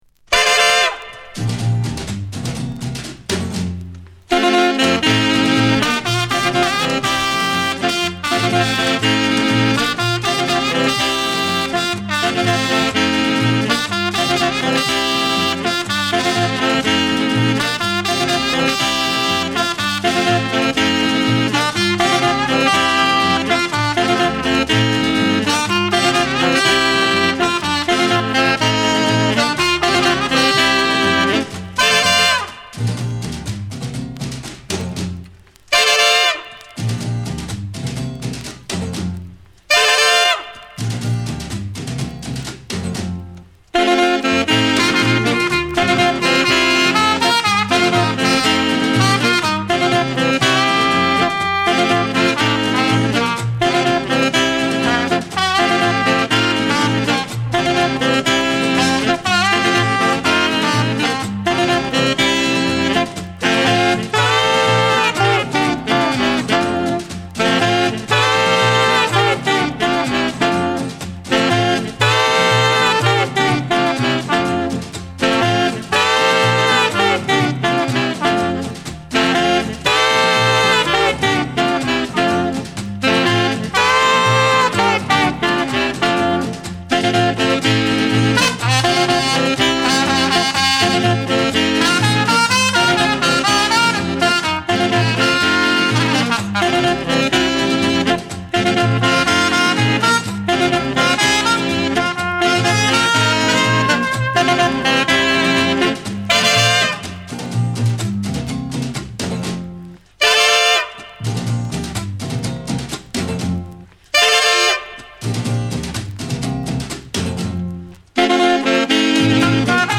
saxophone, pennywhistle